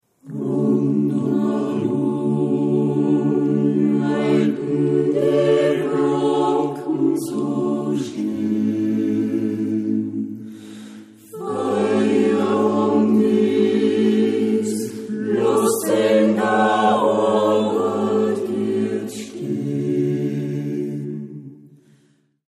Musik aus dem Mostviertel
Aufgenommen im Tonstudio